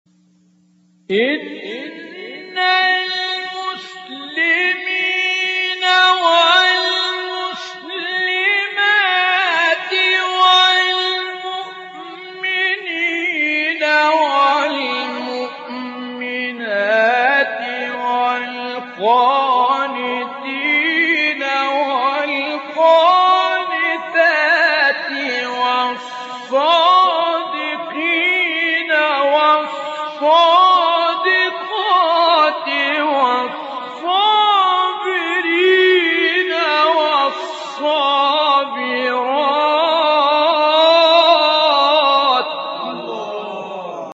گروه شبکه اجتماعی: مقاطع صوتی از سوره احزاب با صوت سید متولی عبدالعال ارائه می‌شود.
به گزارش خبرگزاری بین المللی قرآن (ایکنا) فرازهایی صوتی از سوره مبارک احزاب با صدای سید متولی عبدالعال، قاری به نام مصری در کانال تلگرامی تلاوت ناب منتشر شده است، در زیر ارائه می‌شود.